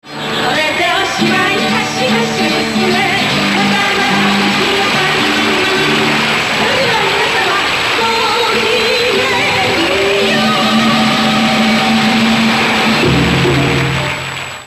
♪エンディングテーマ♪